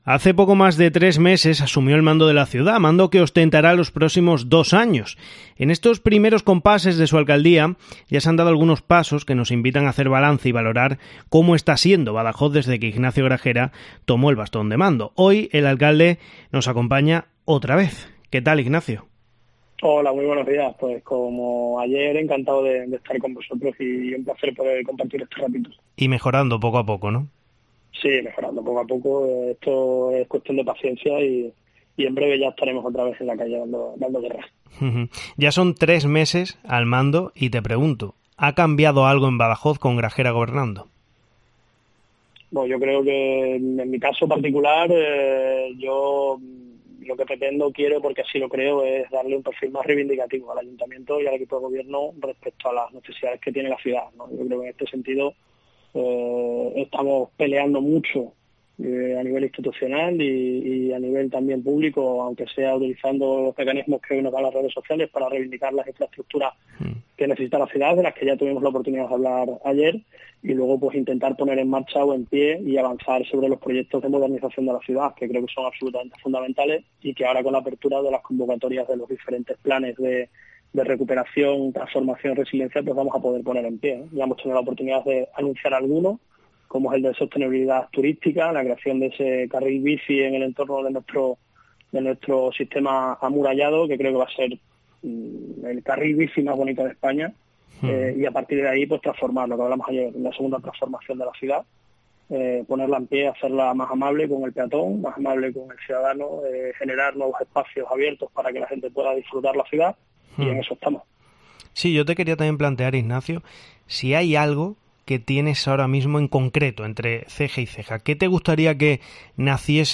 Redacción digital Madrid - Publicado el 01 oct 2021, 11:29 - Actualizado 18 mar 2023, 01:58 2 min lectura Descargar Facebook Twitter Whatsapp Telegram Enviar por email Copiar enlace El alcalde de Badajoz, Ignacio Gragera, ha concedido a Cope una amplia entrevista donde ha repasado sus tres meses al mando de la ciudad y ha valorado la situación de varias problemáticas que afectan directamente a la población pacense.